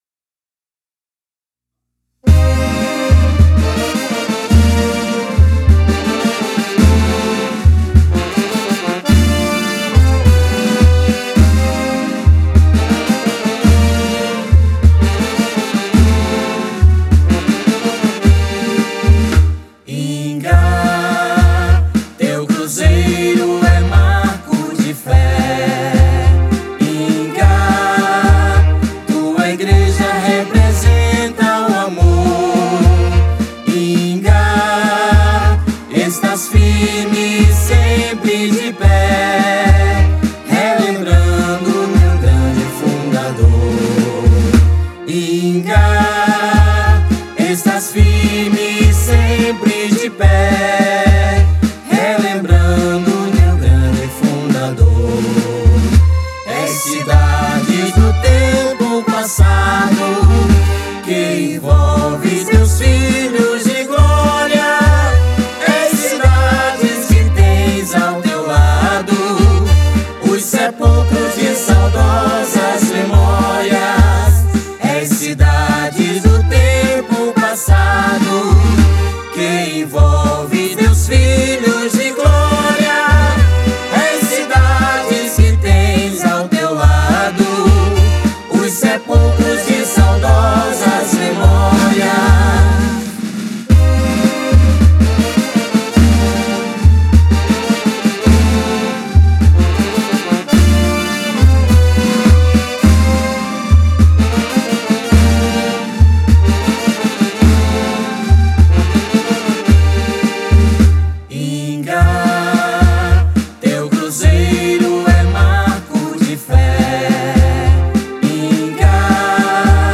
Prefeitura de Ingá regrava Hino da cidade com Instrumental e Voz
O projeto contou com músicos ingaenses, que participaram de forma voluntária, doando seu talento em nome do amor por nossa terra.